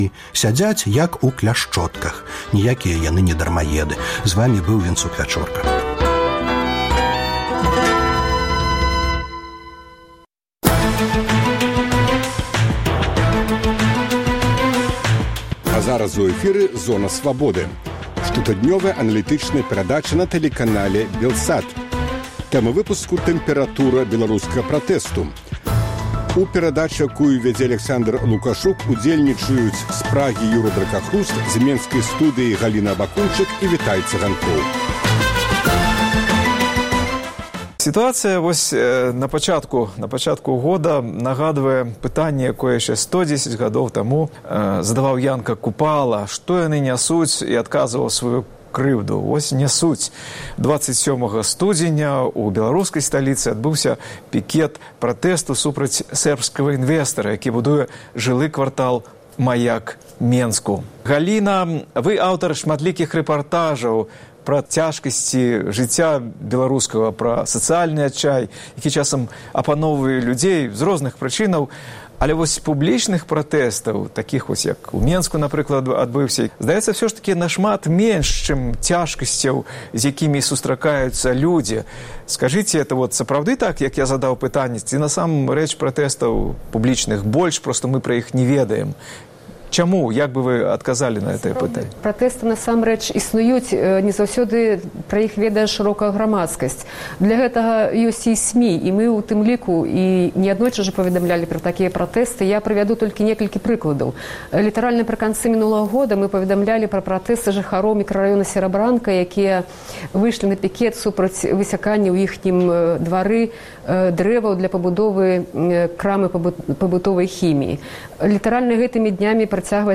Зона Свабоды - штотыднёвая аналітычная перадача на тэлеканале Белсат Як у Беларусі дамагчыся праўды? Дзе мяжа цярпеньня беларусаў? Якая «тэмпэратура» пратэсту ў беларускім грамадзтве?